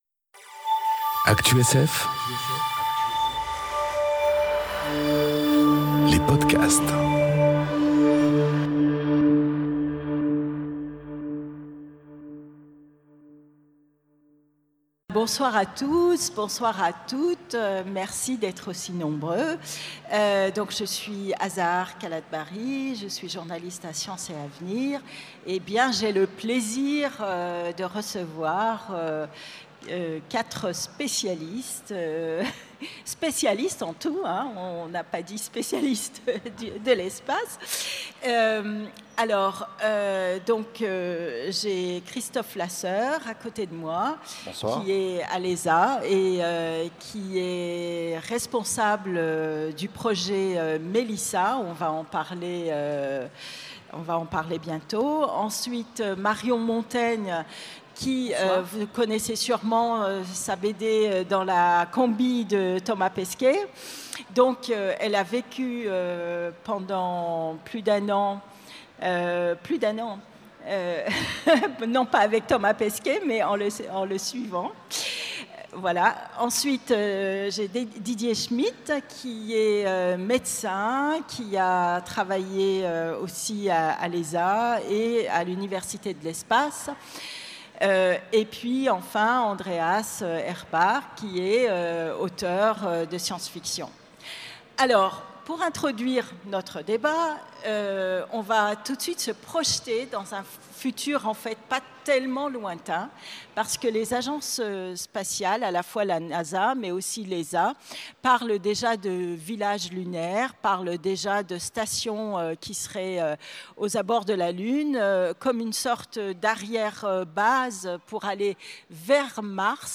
Conférence Le corps dans l’espace enregistrée aux Utopiales 2018